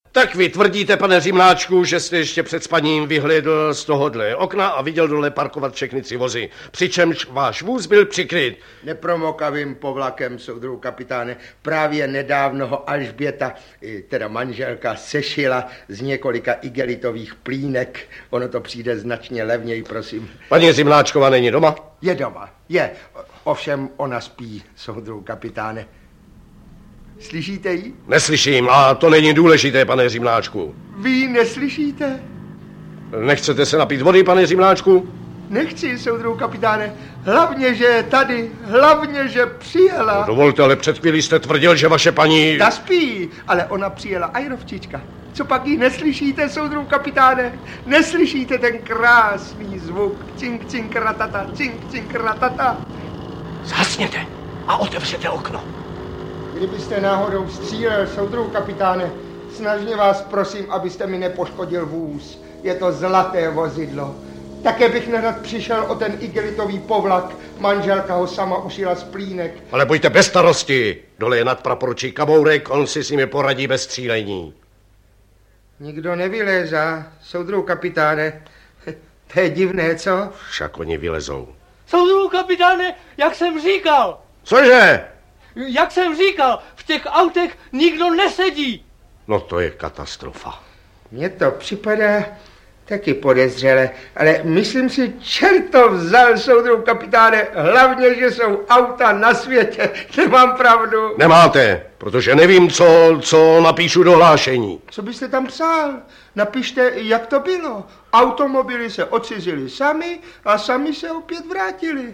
Pohádky audiokniha